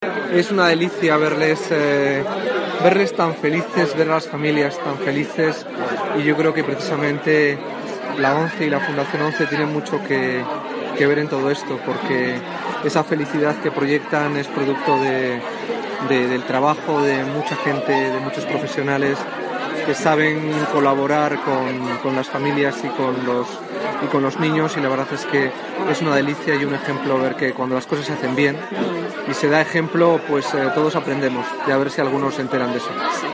Se celebró este acto en el Centro de Recursos Educativos de la Organización en la capital para homenajear a los ganadores del concurso en las ediciones de 2015 y 2016.
Presente también en el evento, el secretario de Estado de Cultura del Gobierno en funciones, José María Lasalle, resaltó su satisfacción por comprobar la felicidad de los chavales y
jose-maria-lasalle-secretario-de-estado-de-educacion